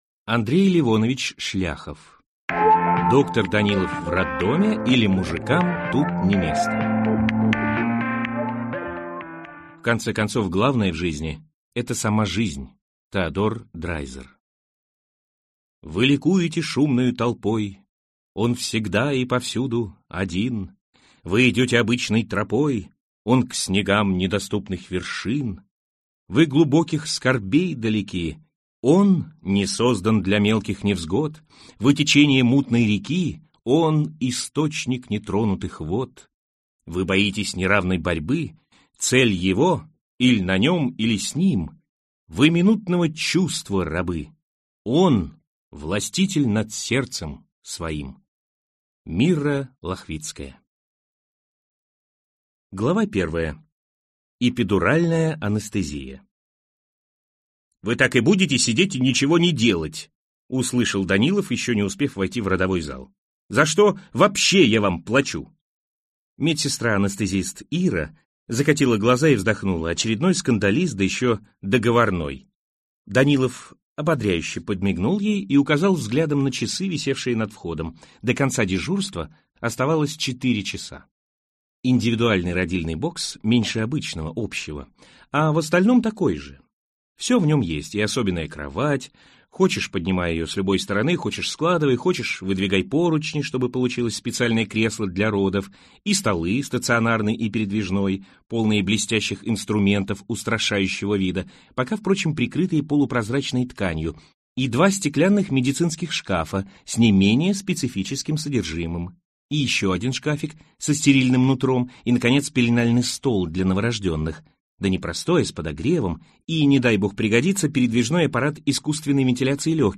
Аудиокнига Доктор Данилов в роддоме, или Мужикам здесь не место | Библиотека аудиокниг